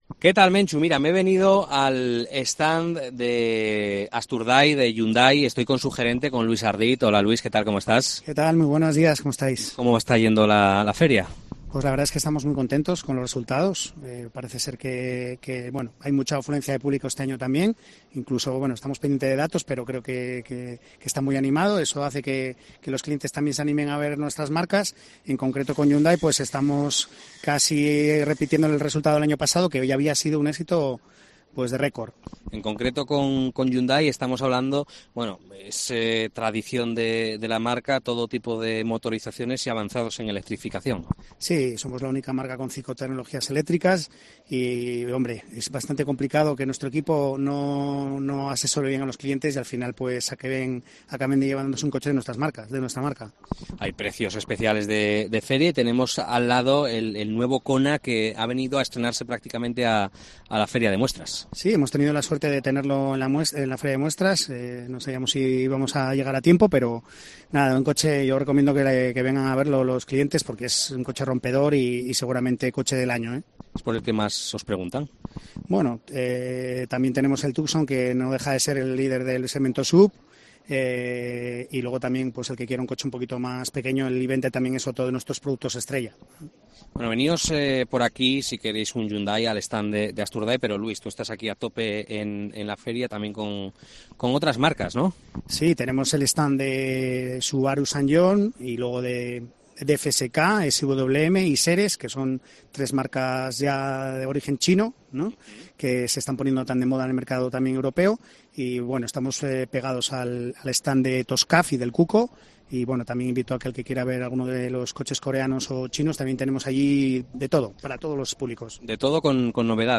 FIDMA 2023: entrevista